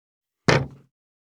187,コトン,トン,ゴト,ポン,ガシャン,ドスン,ストン,カチ,タン,バタン,スッ,サッ,コン,ペタ,パタ,チョン,コス,カラン,ドン,チャリン,
コップ効果音物を置く
コップ